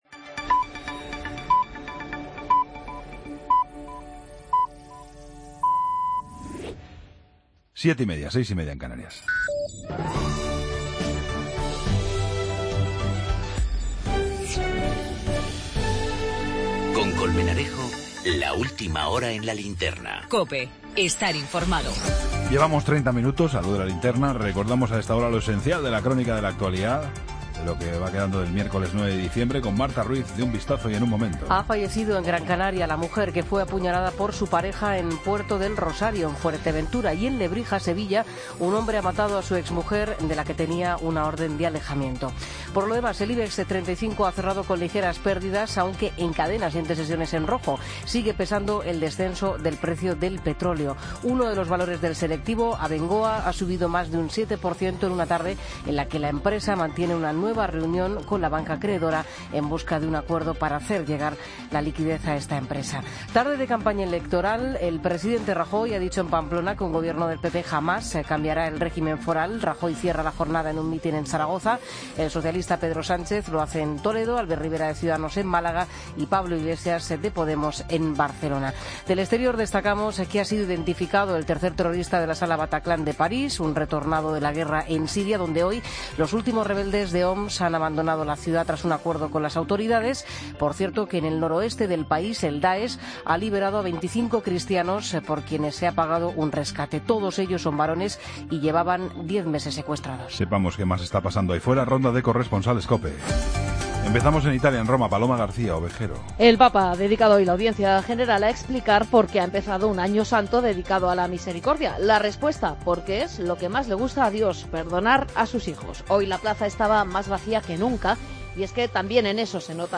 la ronda de corresponsales de Cope y los oyentes